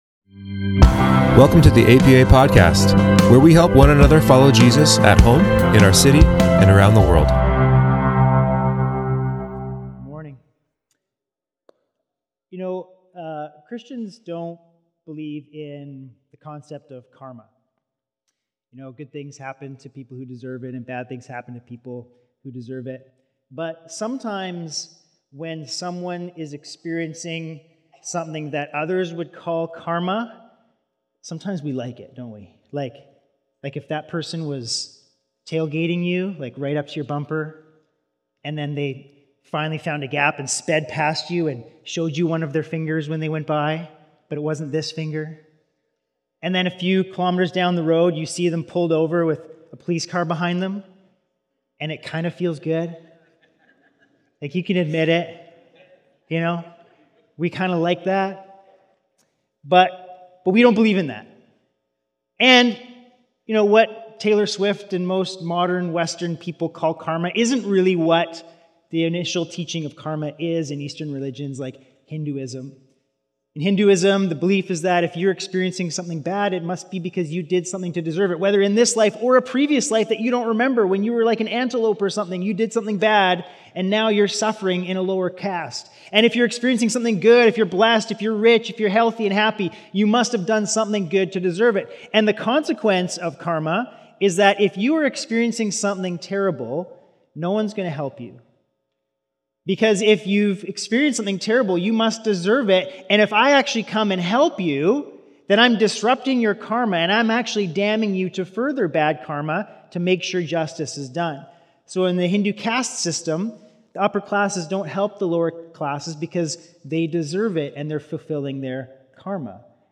Sermons | Abbotsford Pentecostal Assembly